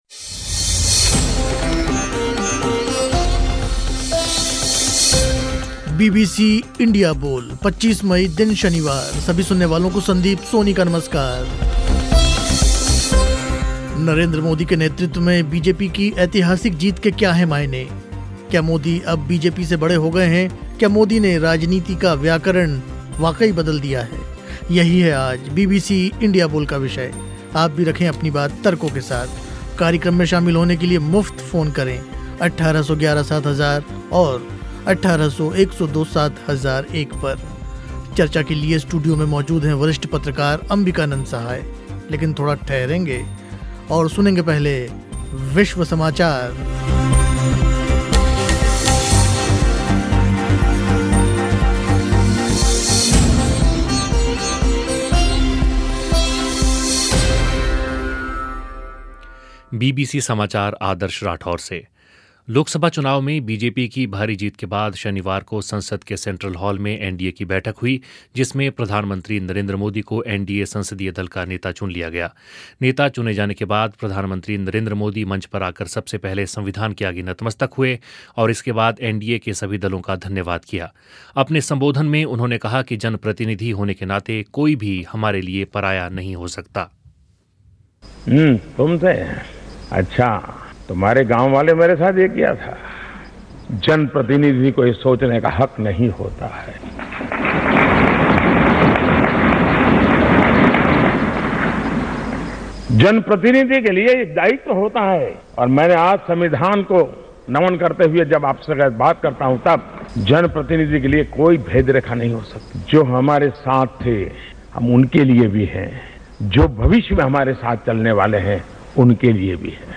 क्या मोदी ने राजनीति का व्याकरण वाकई बदल दिया है? बीबीसी इंडिया बोल में आज चर्चा हुई इसी विषय पर.